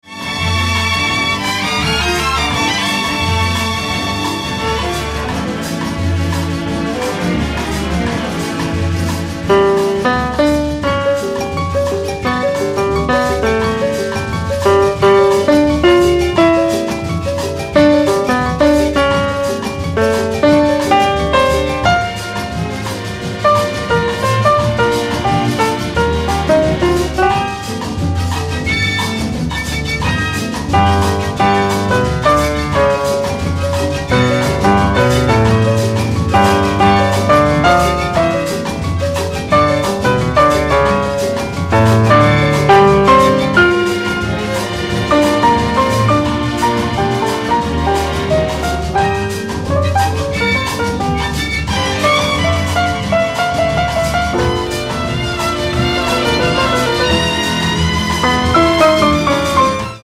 Real Elevator music 🙂